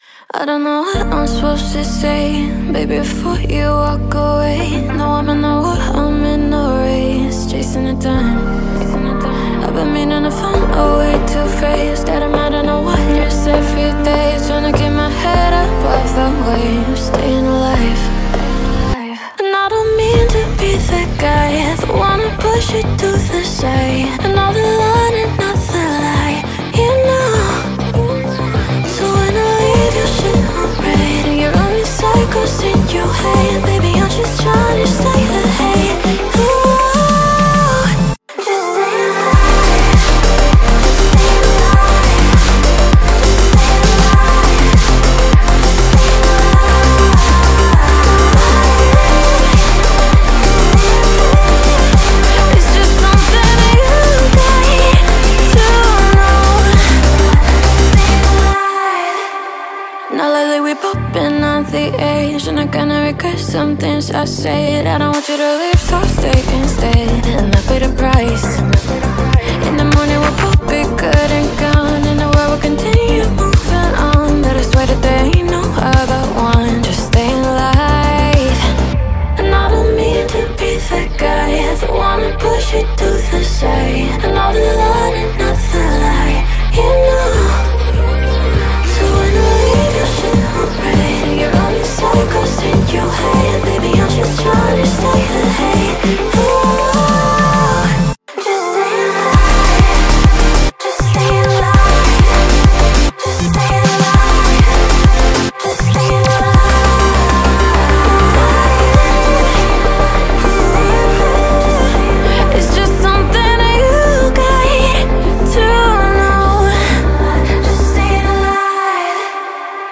BPM100
CommentsELECTRONIC